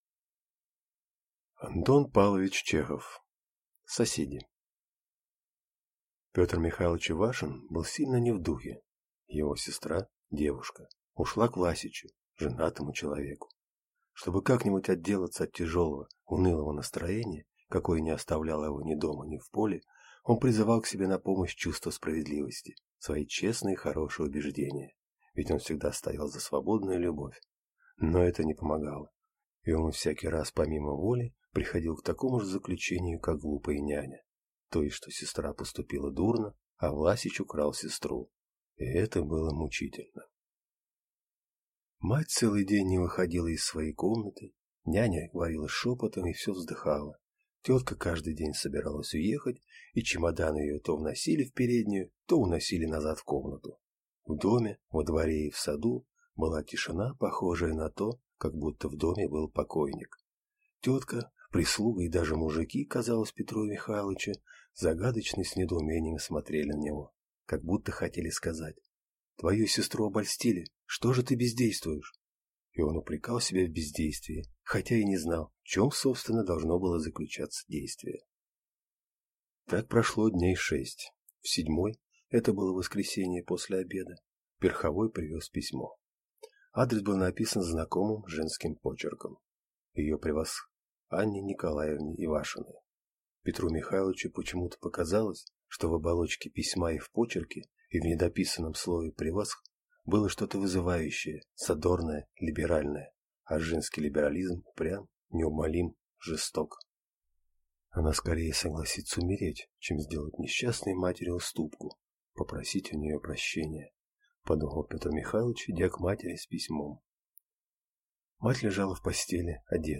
Аудиокнига Соседи | Библиотека аудиокниг